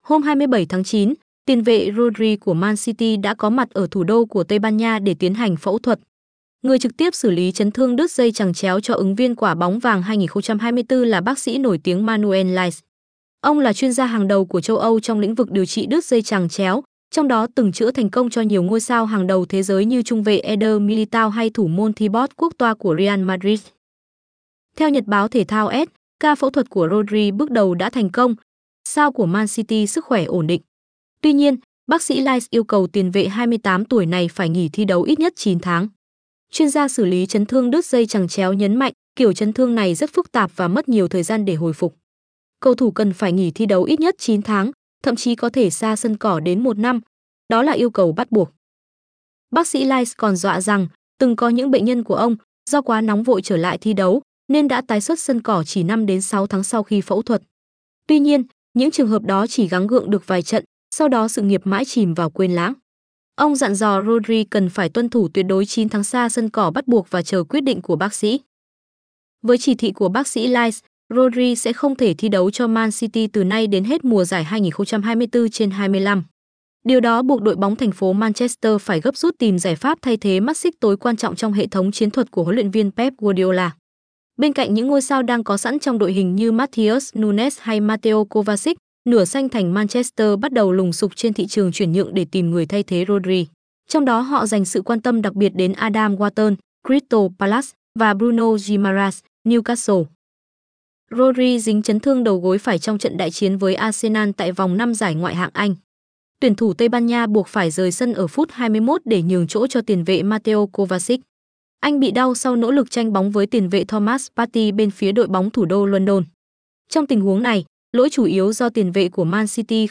Rodri phẫu thuật thành công: Bác sĩ yêu cầu nghỉ 9 tháng 20:23 ngày 27/09/2024 Chia sẻ / x1.5 x1.2 Chọn tốc độ đọc Giọng nữ Giọng nam Tiền vệ Rodri vừa trải qua ca phẫu thuật thành công ở Madrid.